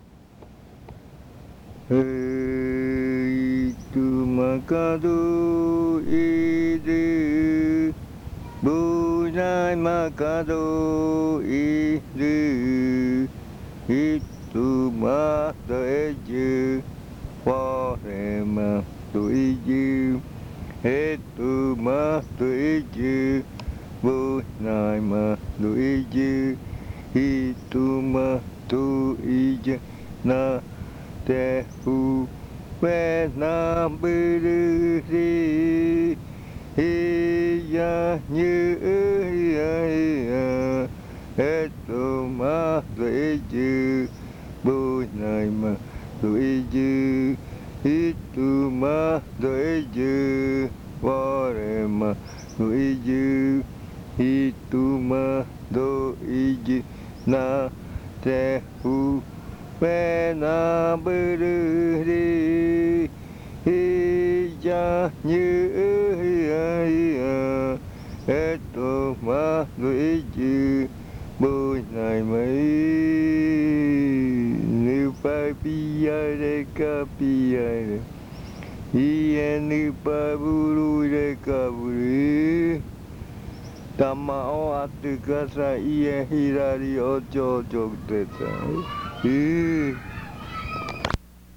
Leticia, Amazonas
Cantos de yuakɨ